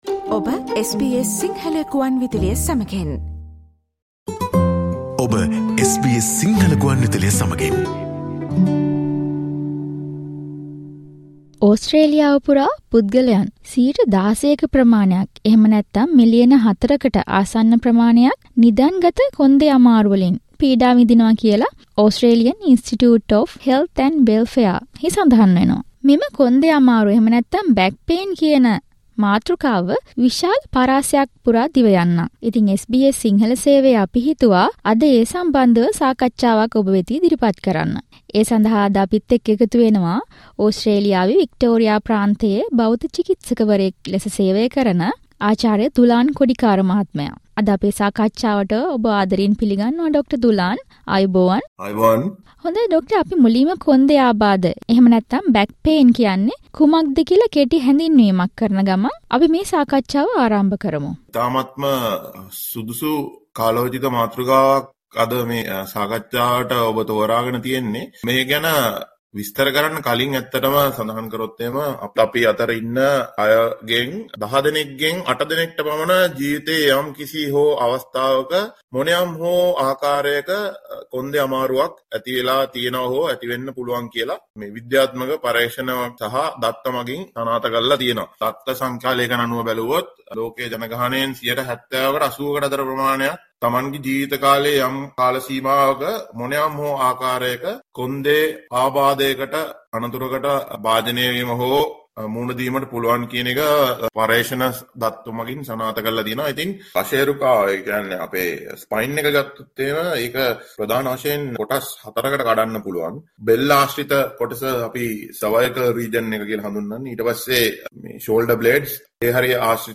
About 16% of Australians (4 million) are currently affected by back problems. Listen to the SBS sinhala discussion on this topic.